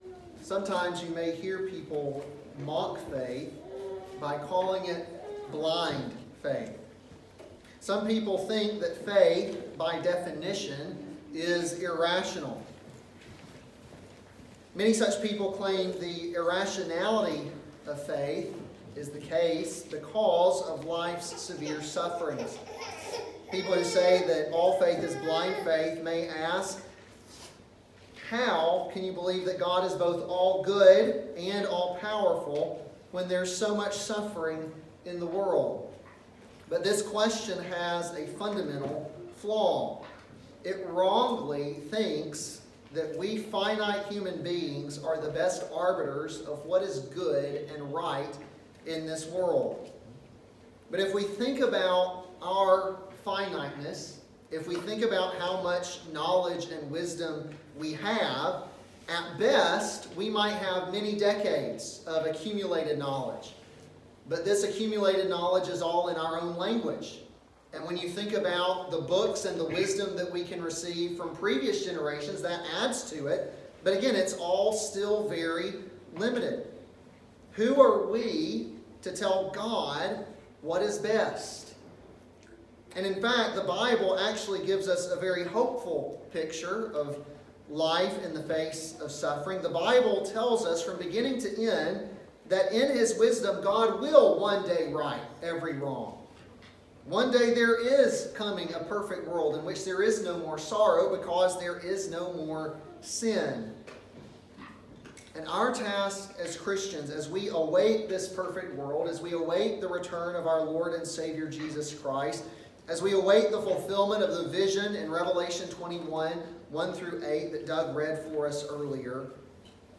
an expository sermon on Isaiah 25:1-26:6